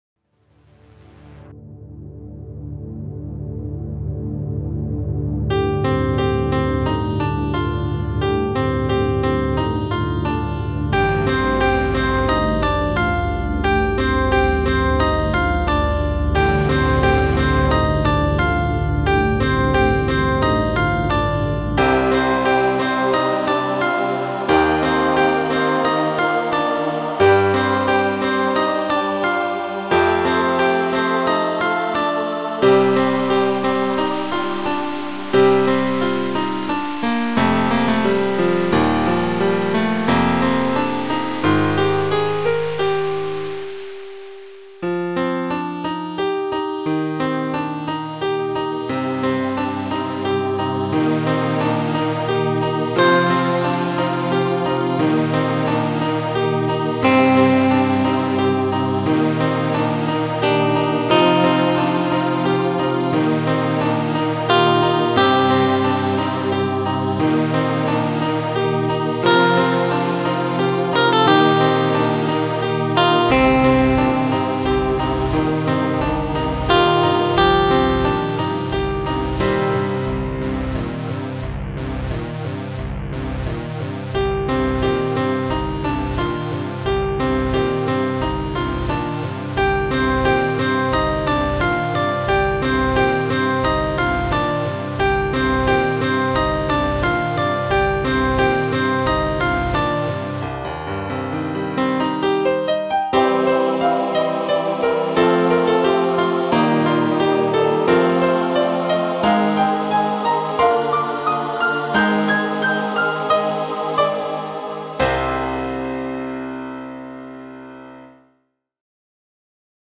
This was composed by me and arranged by my friend who put the synthethizers and create some sounds for the songs. I would like to do this song with less midi sound that it has, but I am quite satisfied for what I create with help of my friends